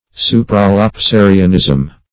Search Result for " supralapsarianism" : The Collaborative International Dictionary of English v.0.48: Supralapsarianism \Su`pra*lap*sa"ri*an*ism\, n. The doctrine, belief, or principles of the Supralapsarians.
supralapsarianism.mp3